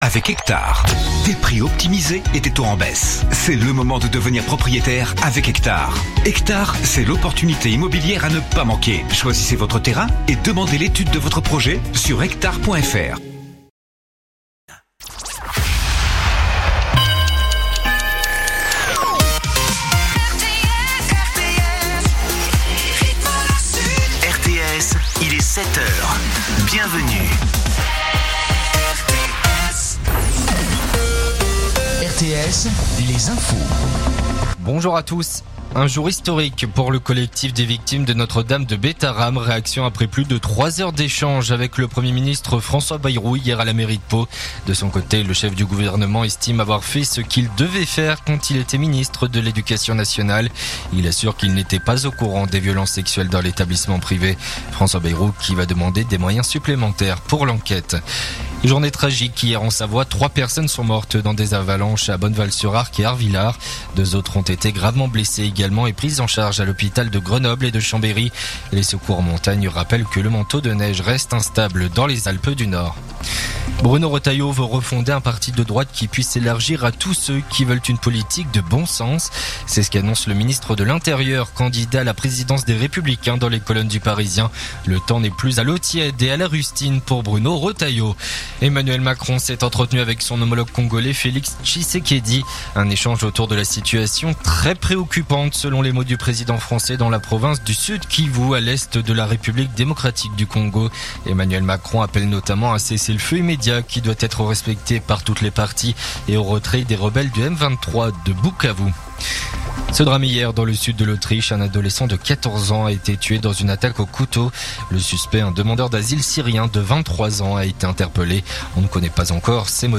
Écoutez les dernières actus de Nîmes en 3 min : faits divers, économie, politique, sport, météo. 7h,7h30,8h,8h30,9h,17h,18h,19h.